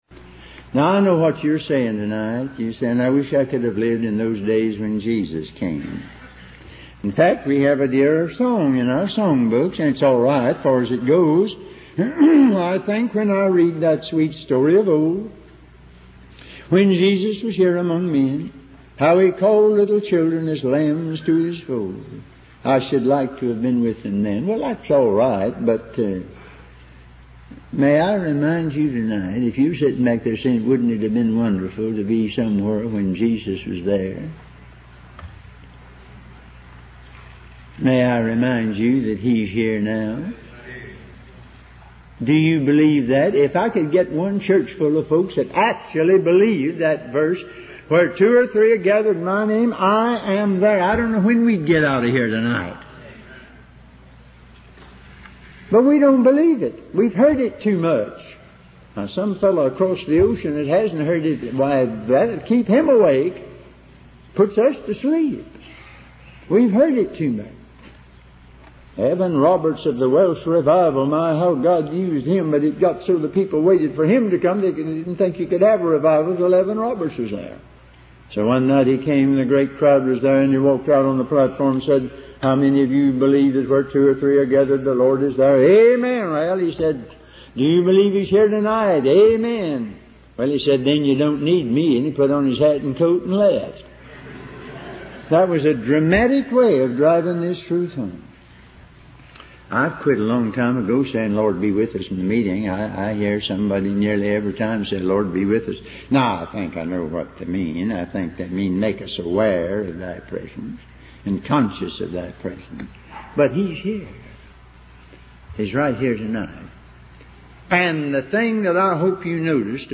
The sermon emphasizes the importance of desperation for God, highlighting the example of the rich young ruler who missed his blessing because he was not desperate.